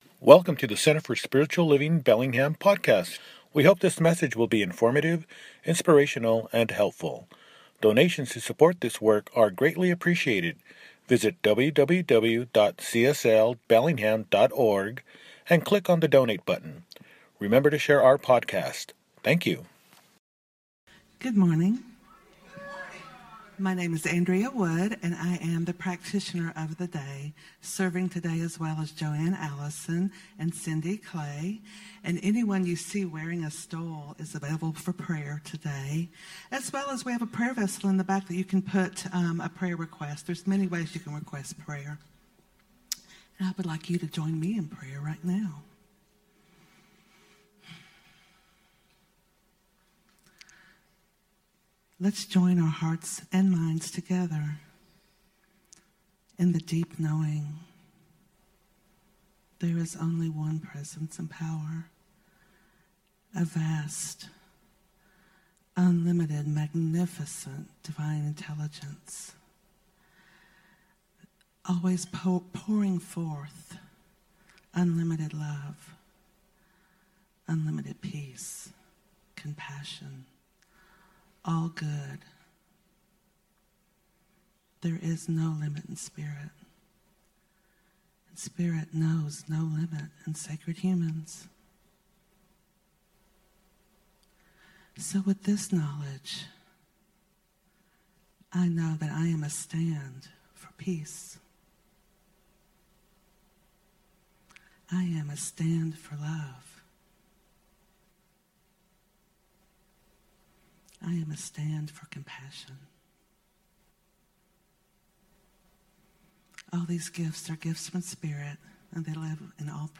Choosing to be For Something – Celebration Service | Center for Spiritual Living Bellingham